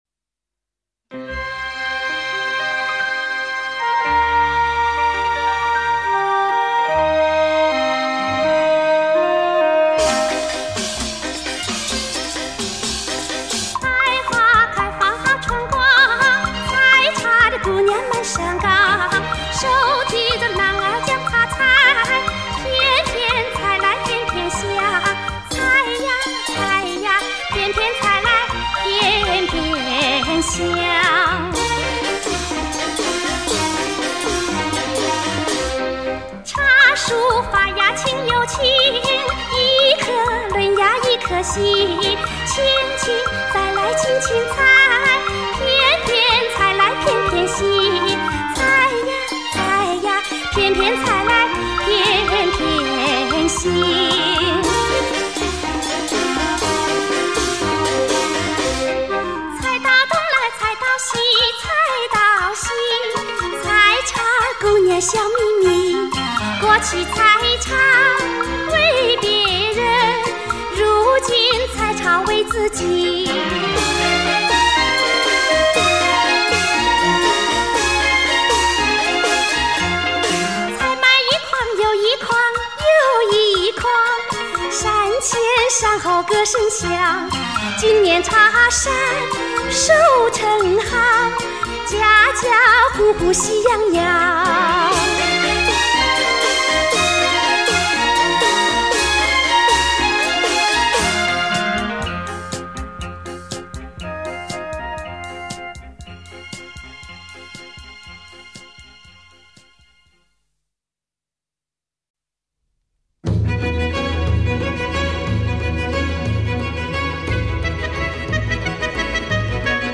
本专辑选取了80-90年代录制的大陆歌唱家演唱的中国各地民歌精选